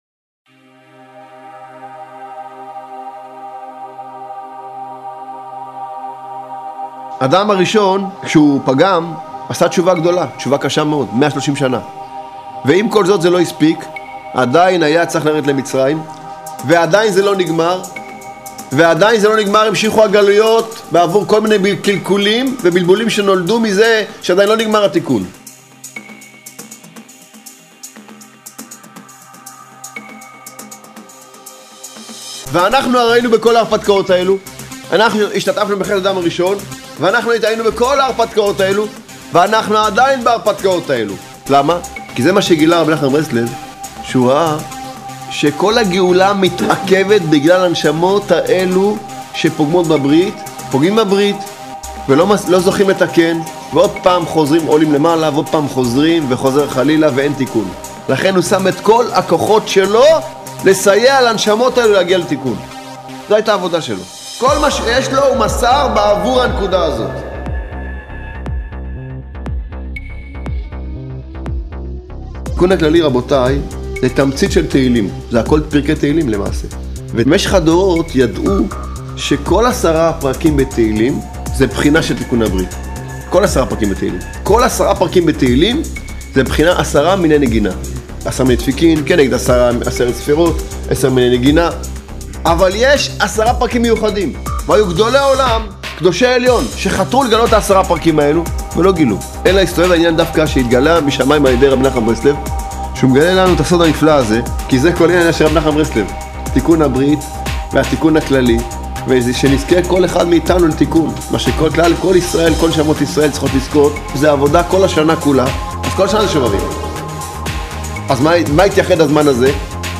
ראשי / שיעורי שמע / ימי השובבים – דיסק מס' 9 ימי השובבים – דיסק מס' 9 פורסם ב: יום רביעי 10 אפריל ,2013 שמע שובבים היא תקופה מיוחדת שנועדה לתיקון הברית, מהם התיקונים המיוחדים?